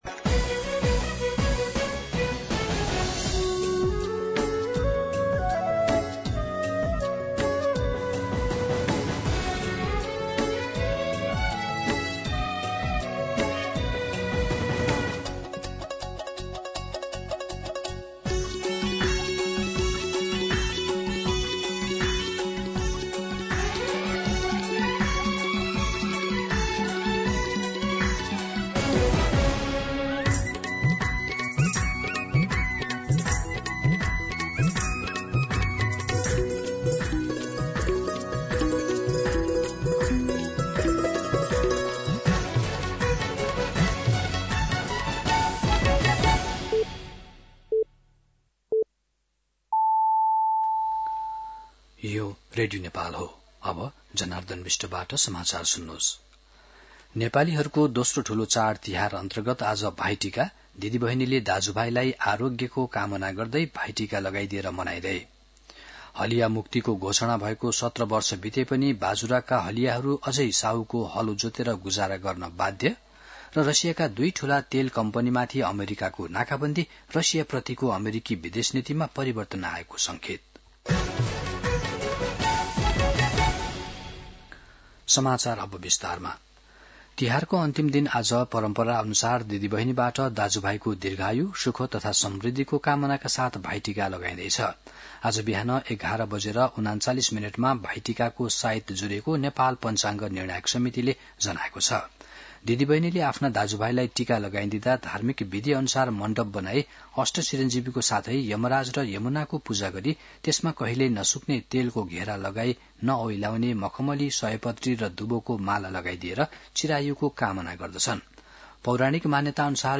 दिउँसो ३ बजेको नेपाली समाचार : ६ कार्तिक , २०८२
3-pm-Nepali-News-12.mp3